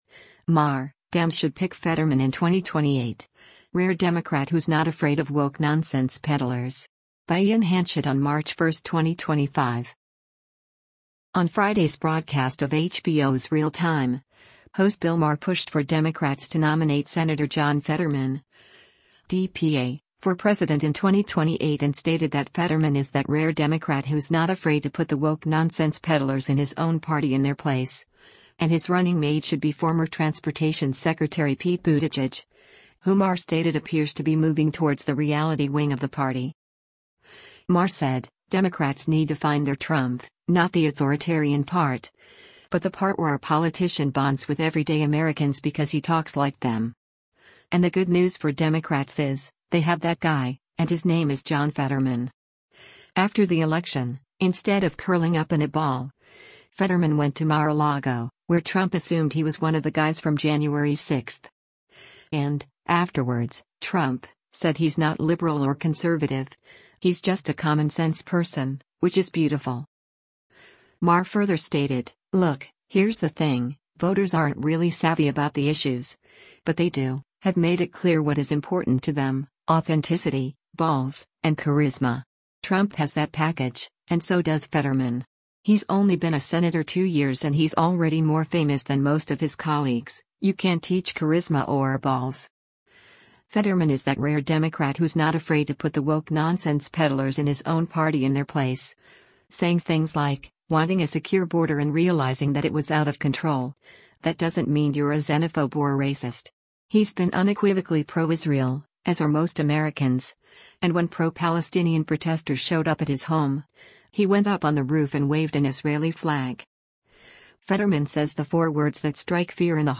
On Friday’s broadcast of HBO’s “Real Time,” host Bill Maher pushed for Democrats to nominate Sen. John Fetterman (D-PA) for president in 2028 and stated that “Fetterman is that rare Democrat who’s not afraid to put the woke nonsense peddlers in his own party in their place,” and his running mate should be former Transportation Secretary Pete Buttigieg, who Maher stated appears to be moving towards “the reality wing” of the party.